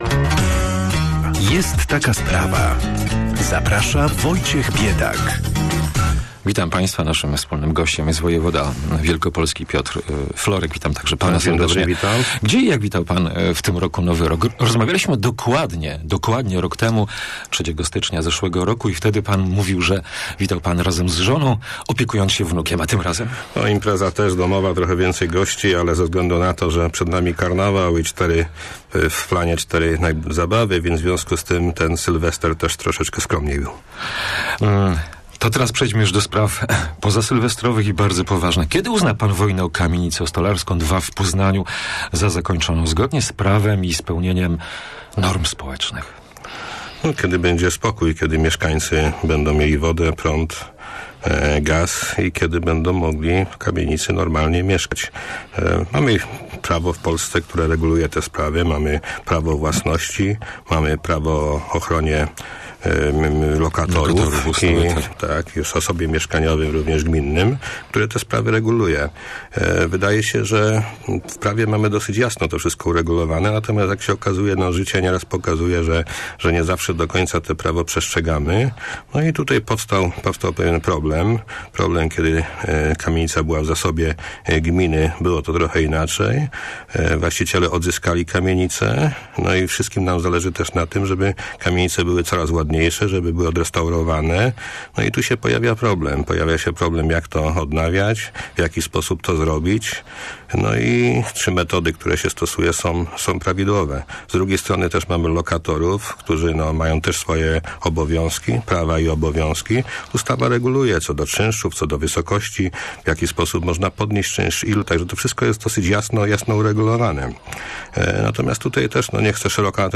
- Możliwe, że symetrii nie ma - przyznaje w rozmowie z Radiem Merkury wojewoda - ale trzeba przede wszystkim rozmawiać.